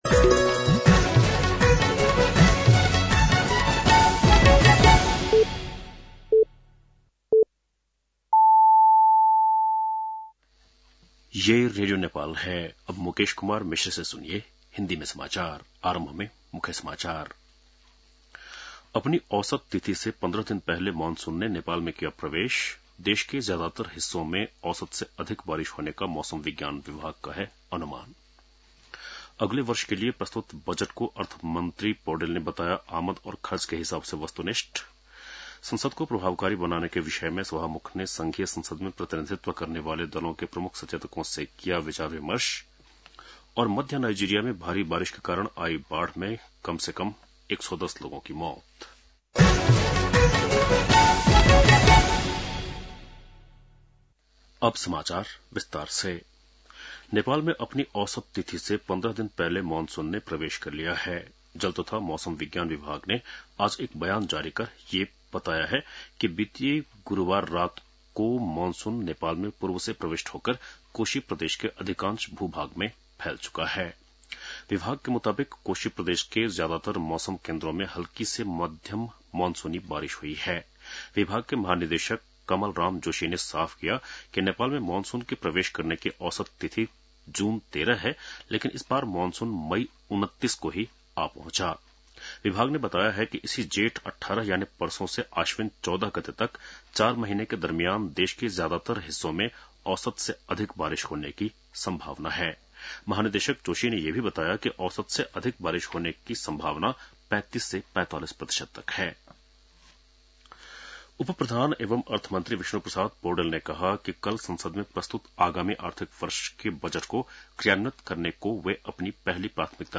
बेलुकी १० बजेको हिन्दी समाचार : १६ जेठ , २०८२